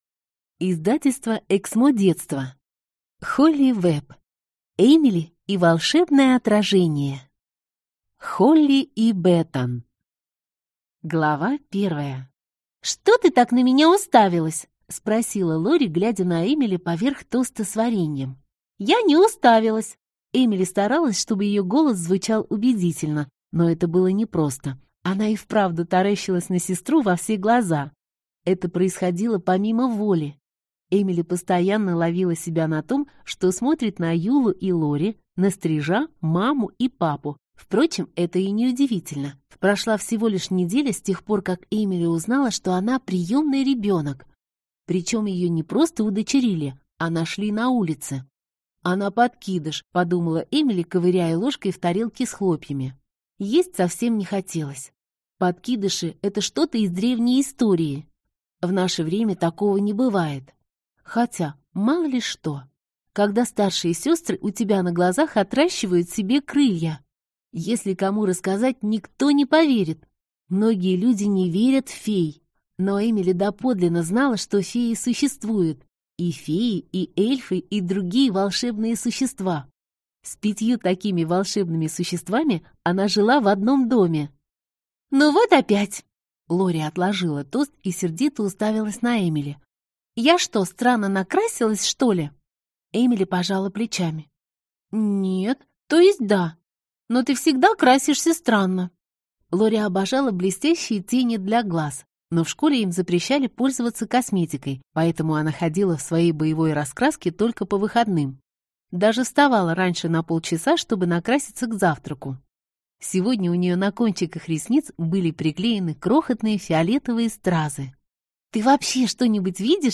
Аудиокнига Эмили и волшебное отражение | Библиотека аудиокниг